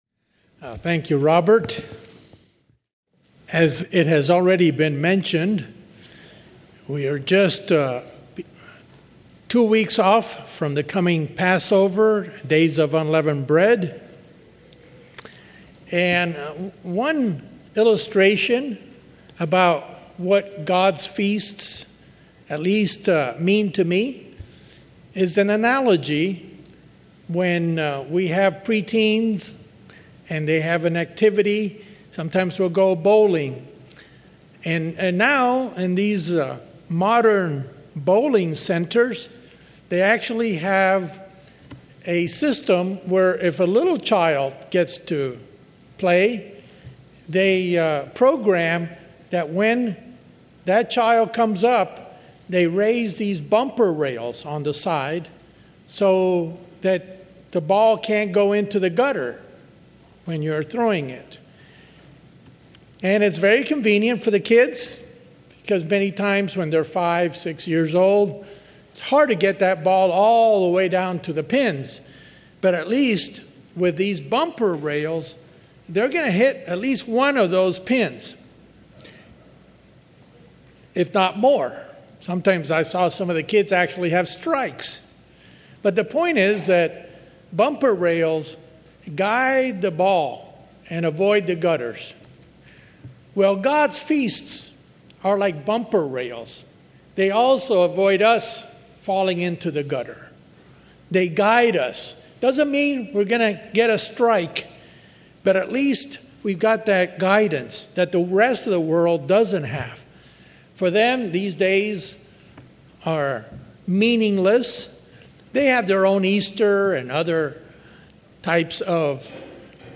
Were the witnesses at Christ's resurrection reliable? Let us find out in this sermon.